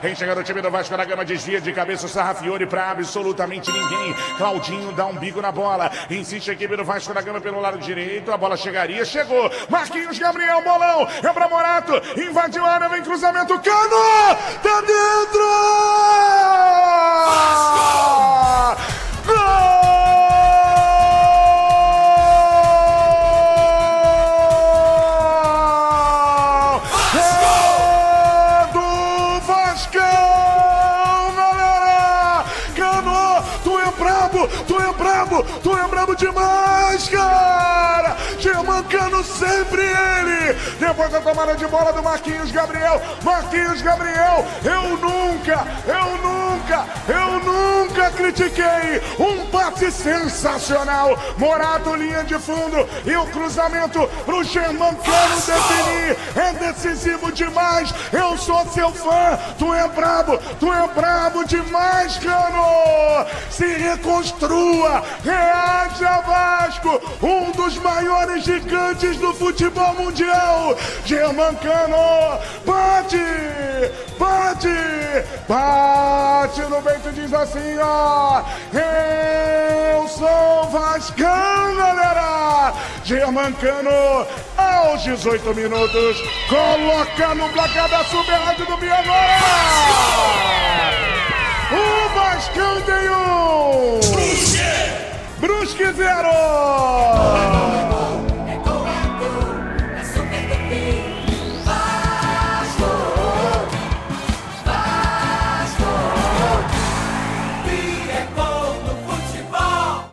GOL-VASCO-1-X-0-BRUSQUE-online-audio-converter.com_.mp3